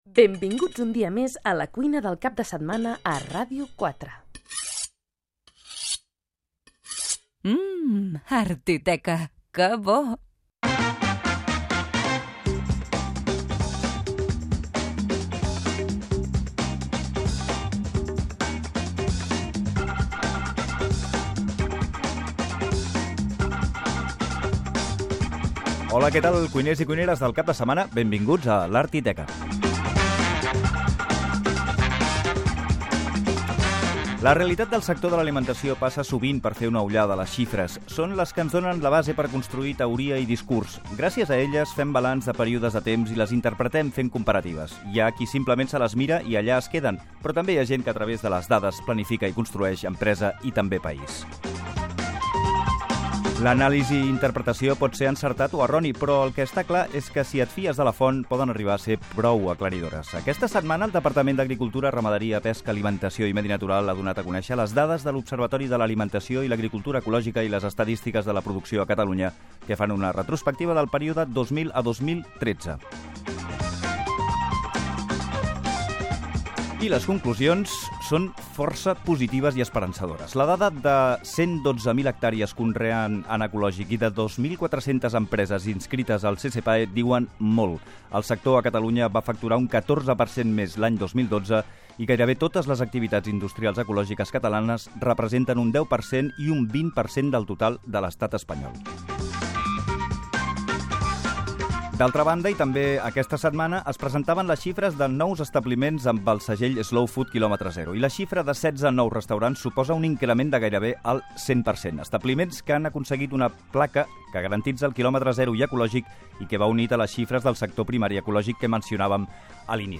Careta del programa, dades sobre el sector de l'alimentació ecològica a Catalunya
Divulgació